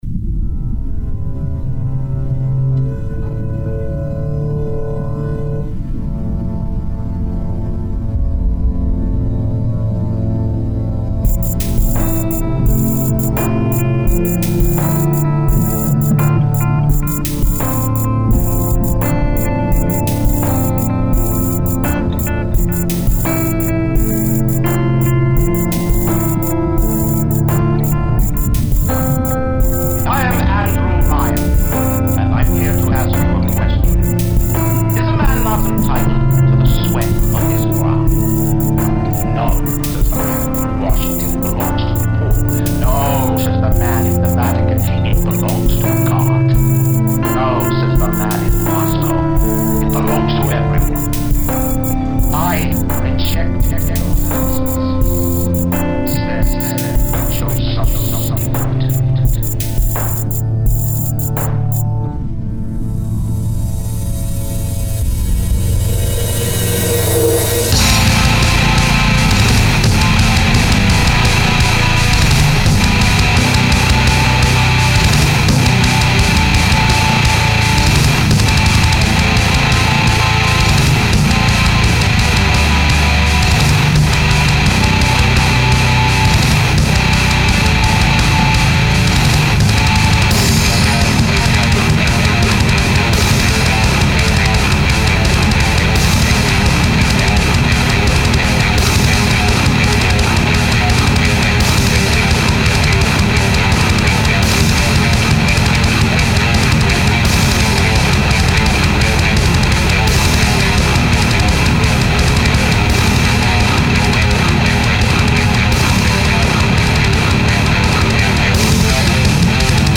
This is my first track using a bass created with the tension instrument in ableton live 8. What can I do to tighten up the guitars a get a chunkier sound?